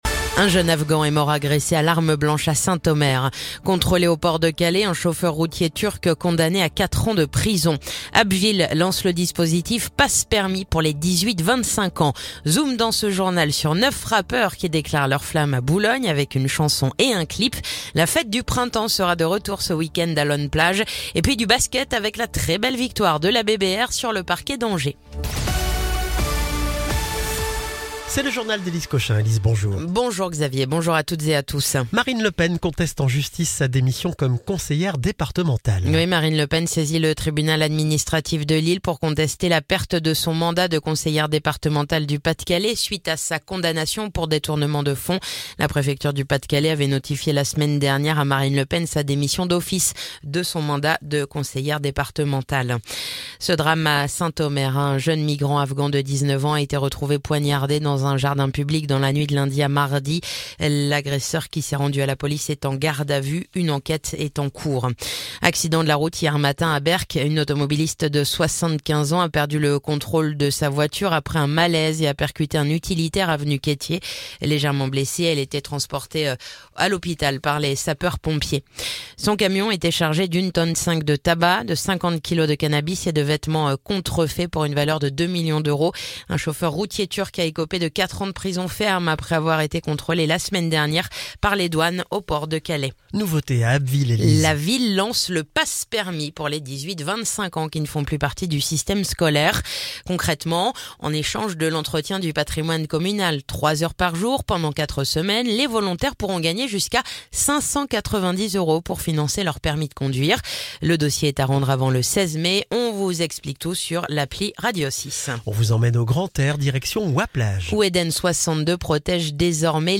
Le journal du mercredi 23 avril